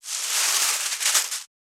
642コンビニ袋,ゴミ袋,スーパーの袋,袋,買い出しの音,ゴミ出しの音,袋を運ぶ音,
効果音